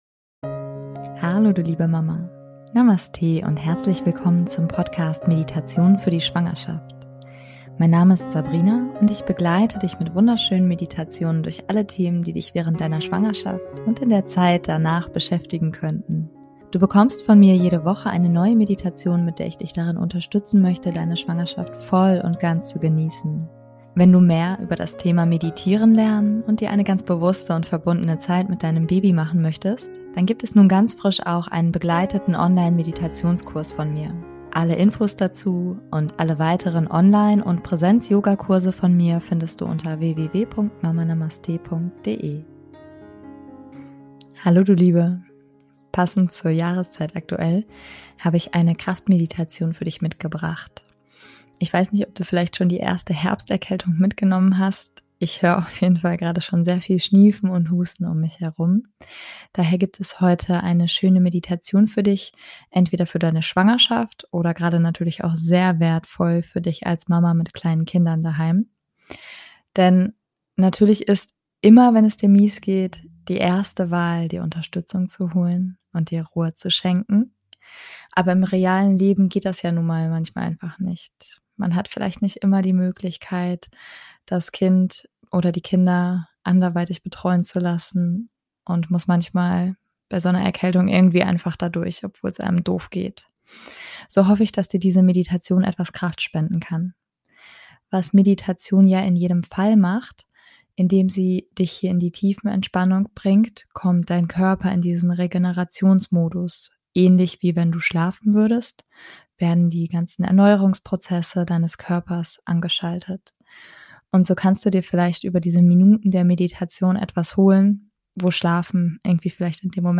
Was Meditation ja in jedem Fall macht: Indem ich dich hier in die Tiefenentspannung leite, kommt dein Körper in den Regenerationsmodus.
Bevor es losgeht, gibt es noch einen internen Werbeblock.